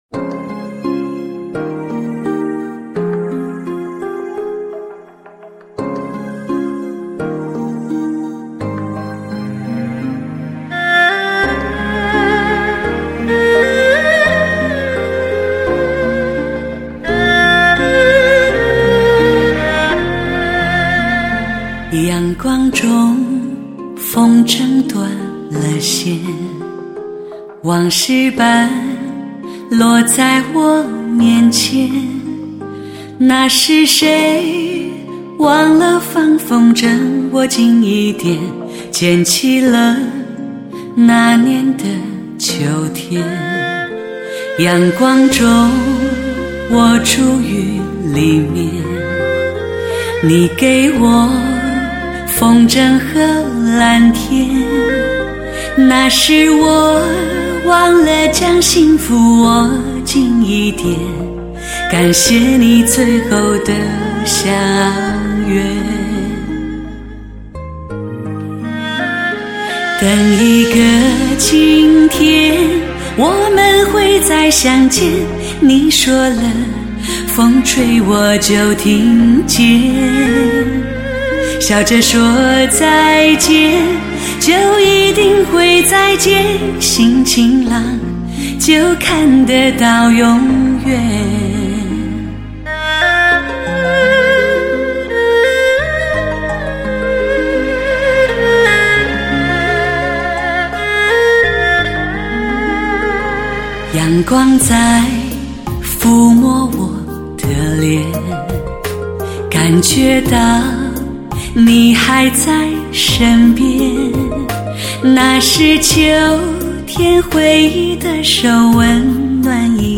全曲无爆音 无断音
专辑格式：DTS-CD-5.1声道
无比优美而充满磁性的人声，一个美好的晴天，带来13首旋律动听的曲目，愉悦的心情，唯美的感觉，那样的平静和谐，让人感动